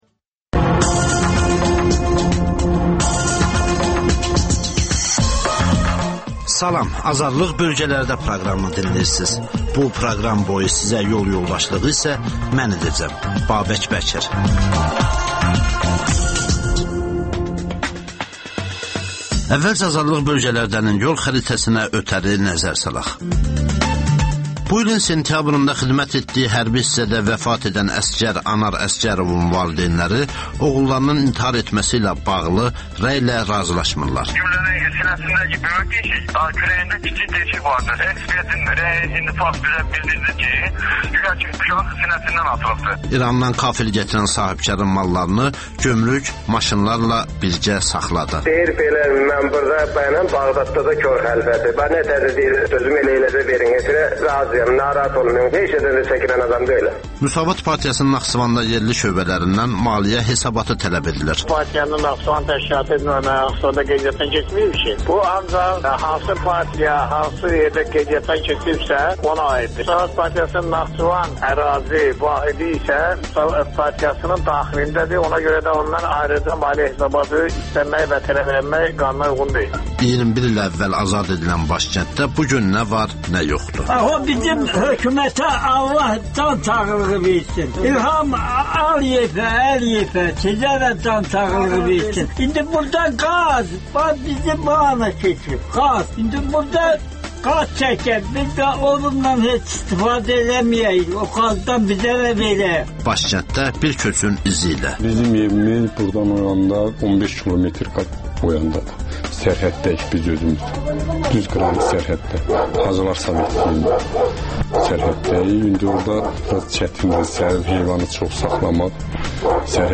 Rayonlardan xüsusi reportajlar.